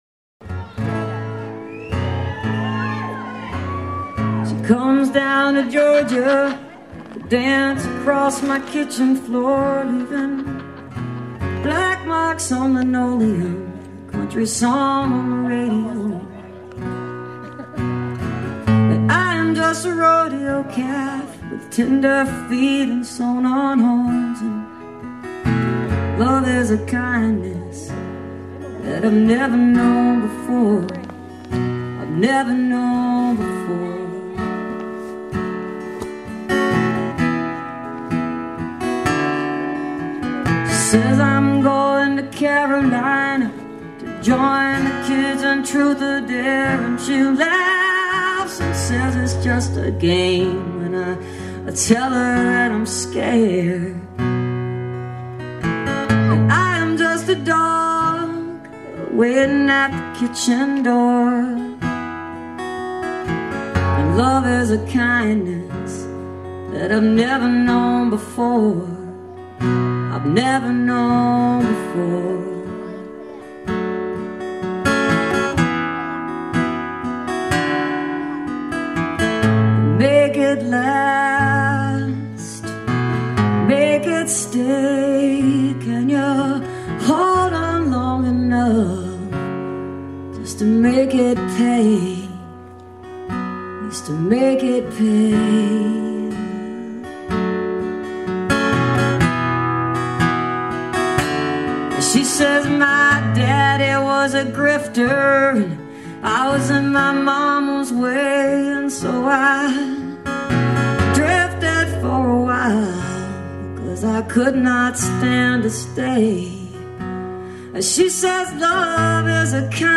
the britt festival - jacksonville, oregon